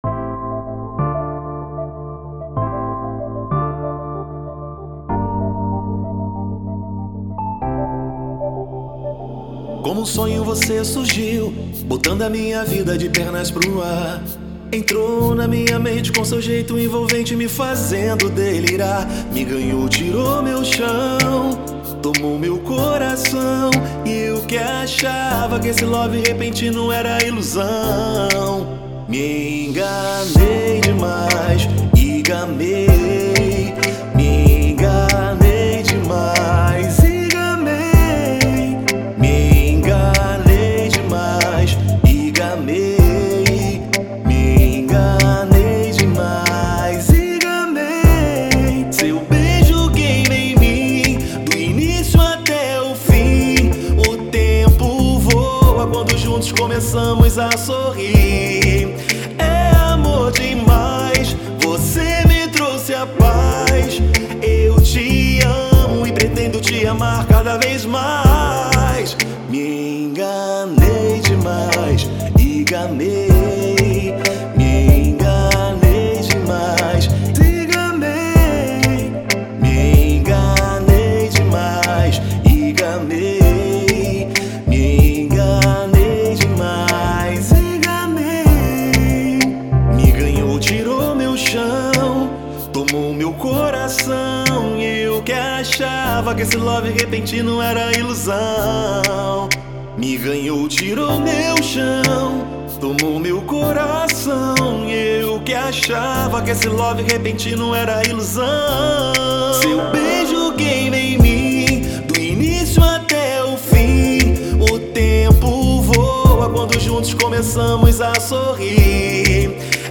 EstiloReggaeton